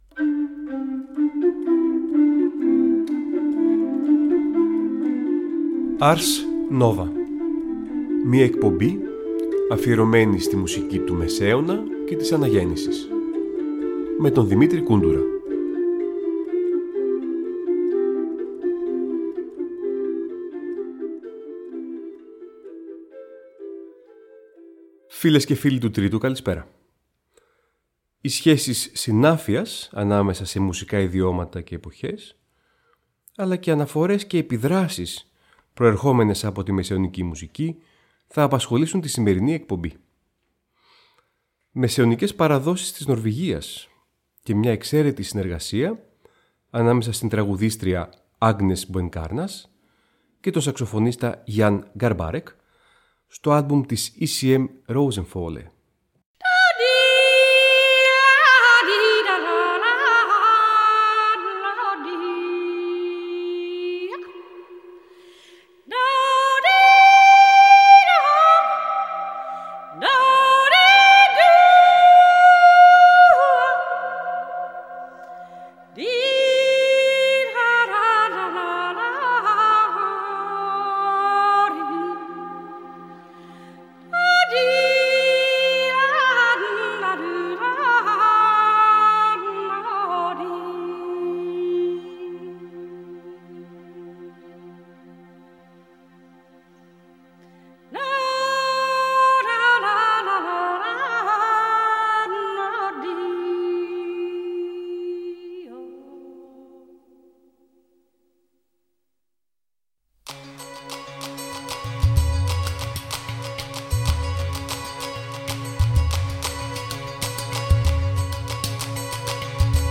Οι Μουσικοί Θησαυροί του Μεσαίωνα και της Αναγέννησης
Νέα ωριαία μουσική εκπομπή του Τρίτου Προγράμματος που μεταδίδεται κάθε Τρίτη στις 19:00.
Μουσικη του Μεσαιωνα